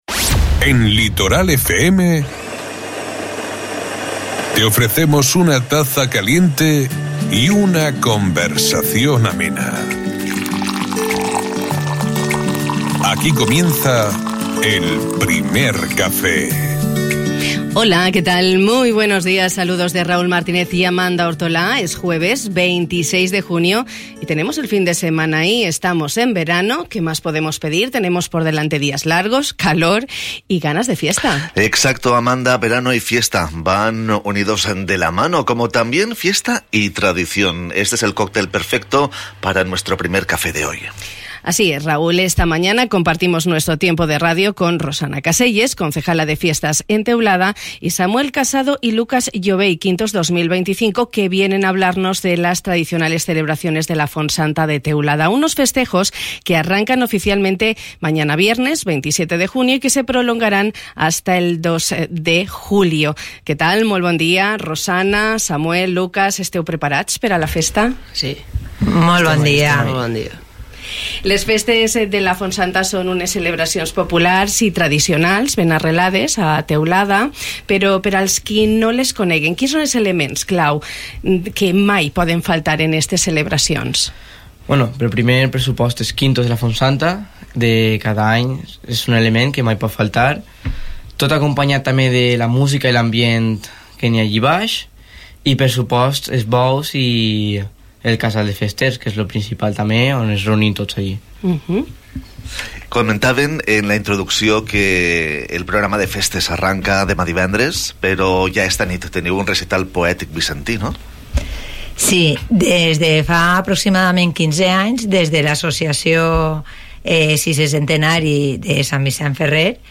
Y de todo esto hemos hablado esta mañana en nuestro Primer Café, poniendo el foco en las celebraciones populares de la ermita de la Font Santa de Teulada.